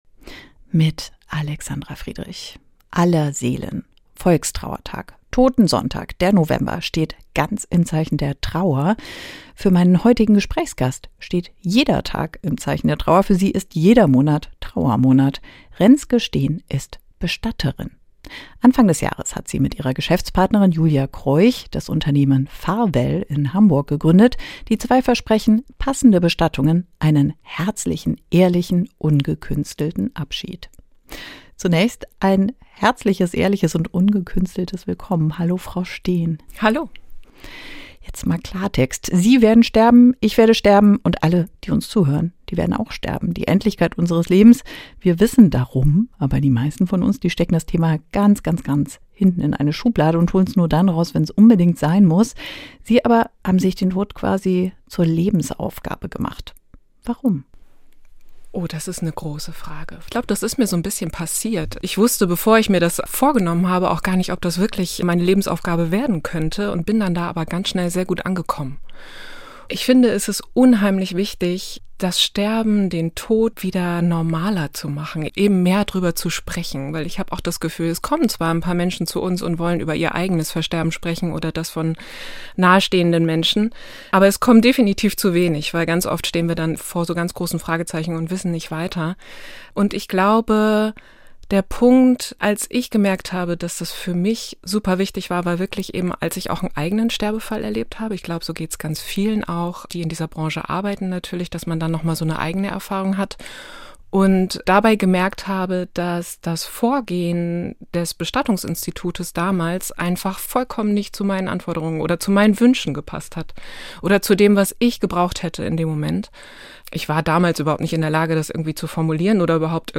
Richtig trauern - Gespräch